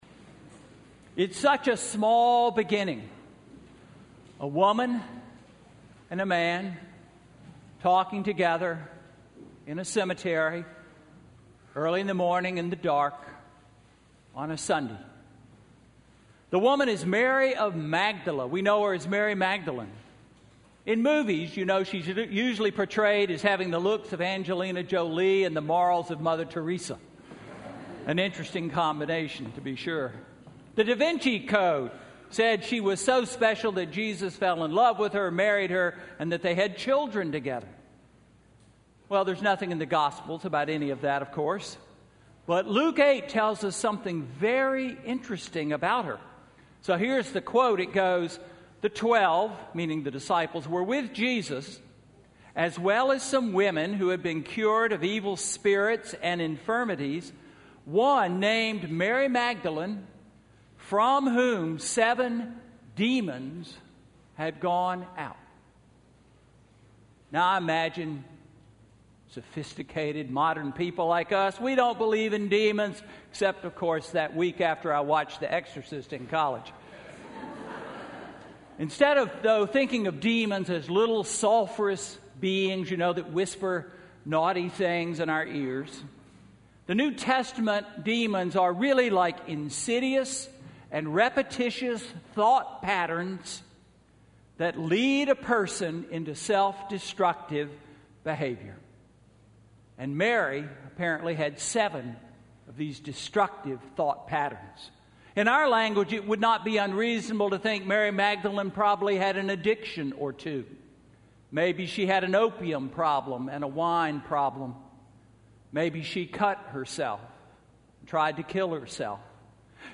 Sermon–Easter Day–March 27, 2016